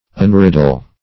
Unriddle \Un*rid"dle\, v. t. & i. [1st pref. un- + riddle.]